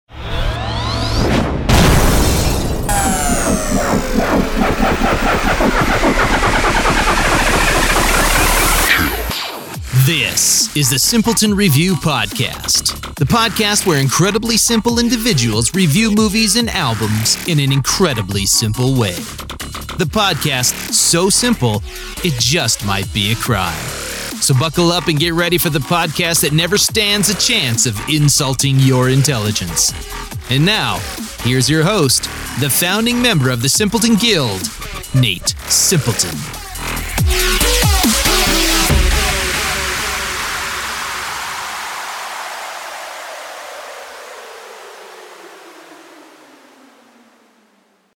A professional voice actor with a warm, trustworthy, and versatile sound.
Podcast Intro